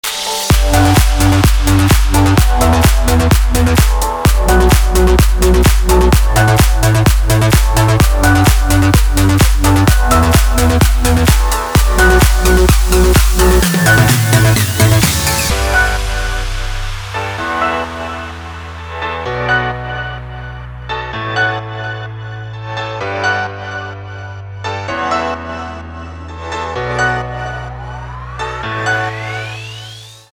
• Качество: 320, Stereo
громкие
красивые
dance
Club House
электронная музыка
без слов
electro house